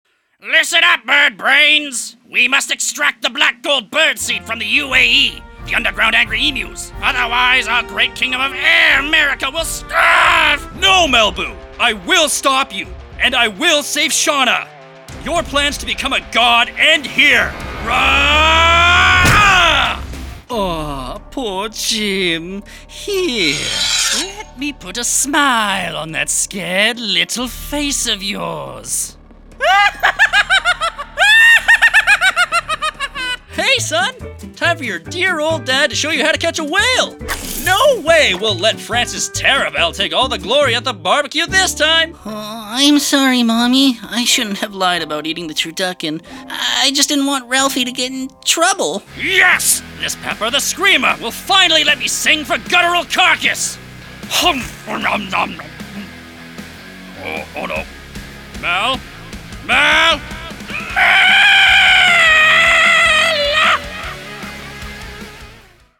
Animation Demo
Canadian
Young Adult